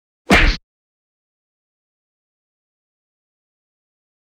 赤手空拳击中肉体7-YS070524.wav
通用动作/01人物/03武术动作类/空拳打斗/赤手空拳击中肉体7-YS070524.wav
• 声道 立體聲 (2ch)